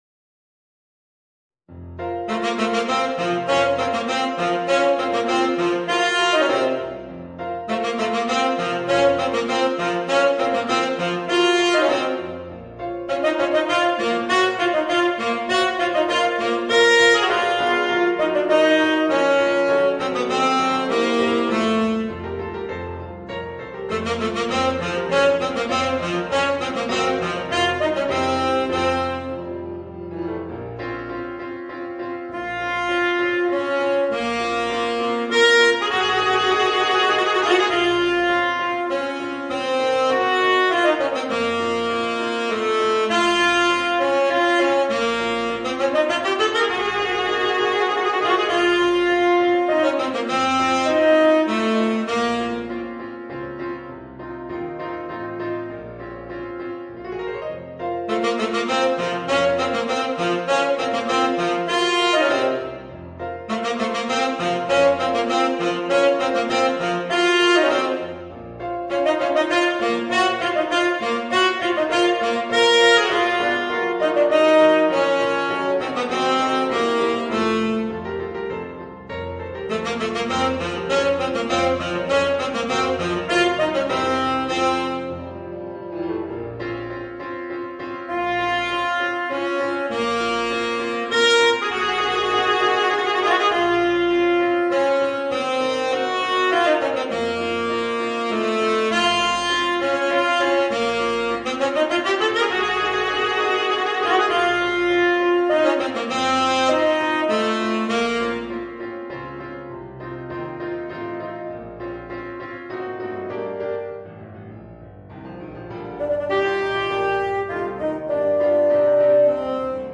Voicing: Tenor Saxophone and Piano